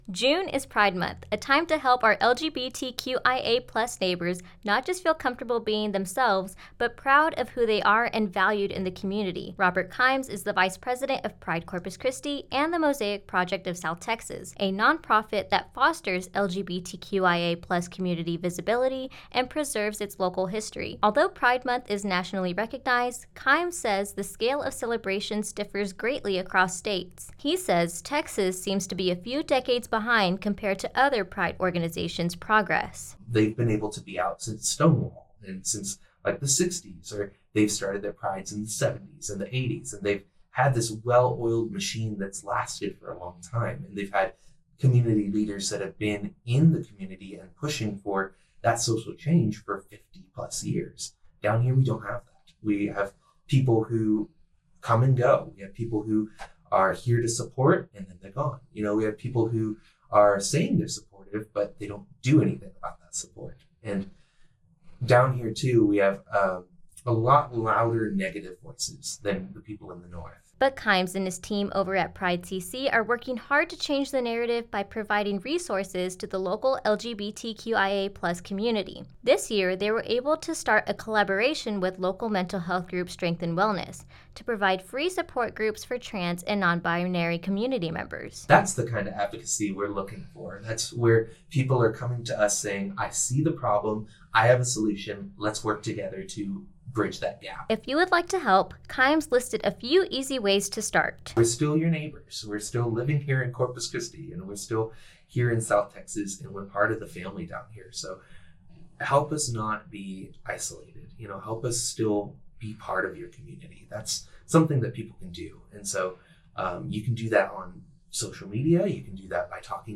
The interview offers more than just analysis, it’s a call to action.